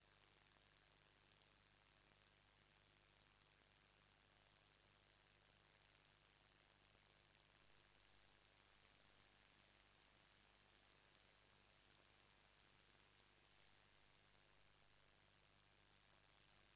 Понял, вообще на слух можно, я не спорю - другой момент, что эта линейка светодиодов прожектором в глаза бьет не прекращая и особенно вечером раздражает И еще момент, думал карта, а это все же сам прибор такие звуки издает, как можно это пофиксить ?